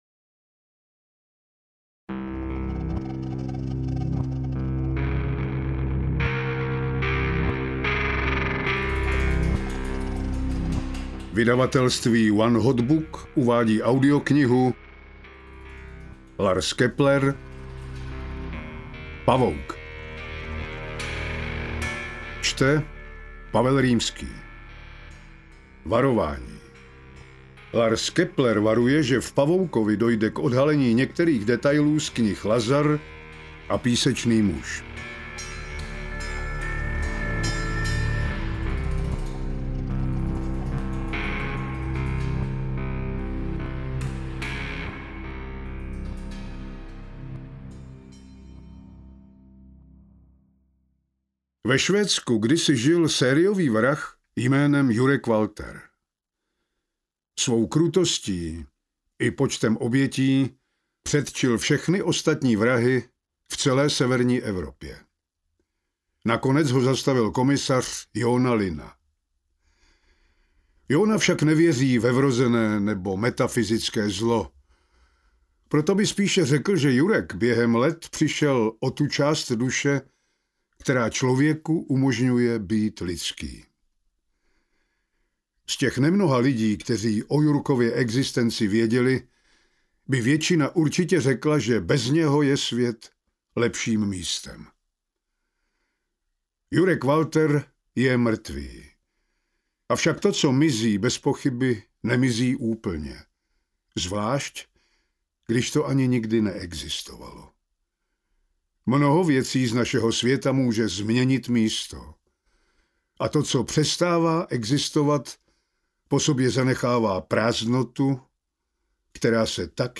Interpret:  Pavel Rimský
AudioKniha ke stažení, 99 x mp3, délka 20 hod. 39 min., velikost 1103,0 MB, česky